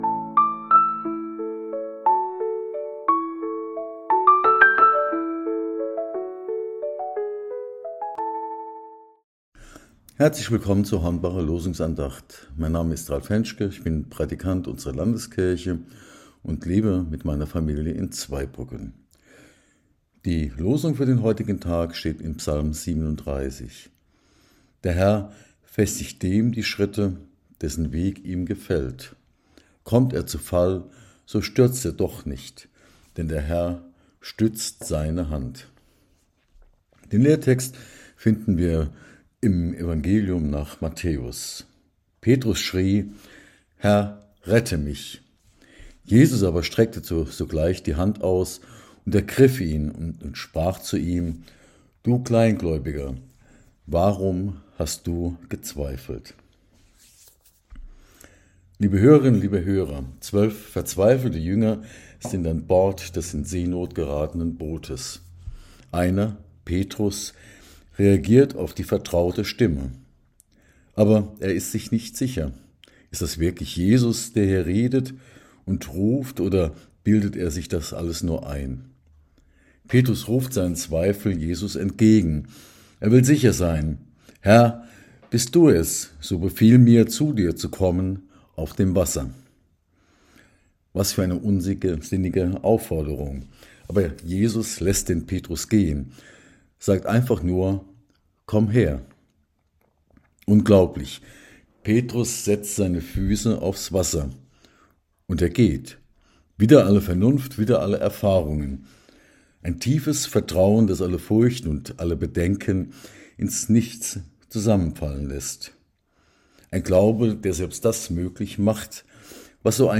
Losungsandacht für Freitag, 13.03.2026 – Prot.